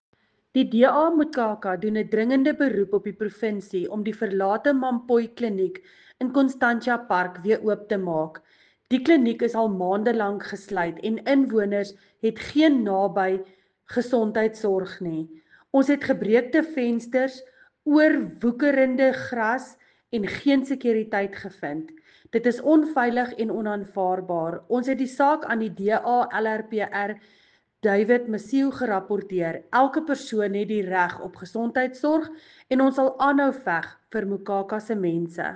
Afrikaans soundbite by Cllr Linda Louwrens.